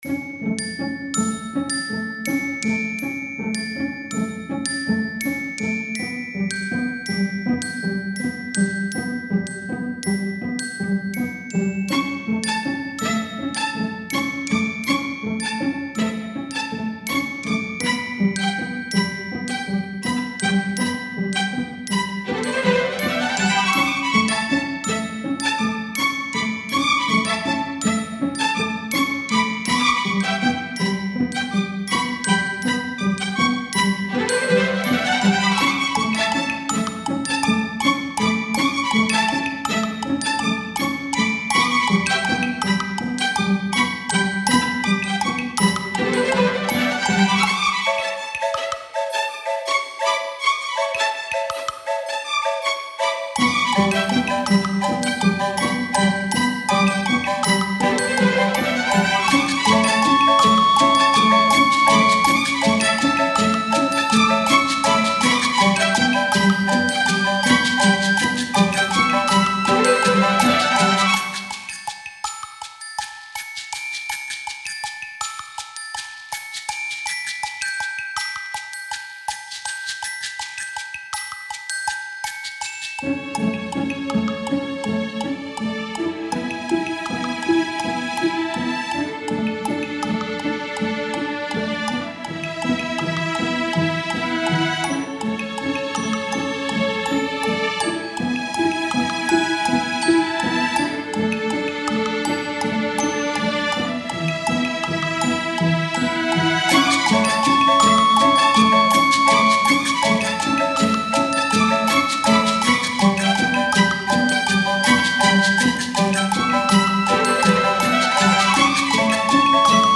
Style Style Orchestral
Mood Mood Bright, Uplifting
Featured Featured Bells, Cello, Glockenspiel +3 more
BPM BPM 162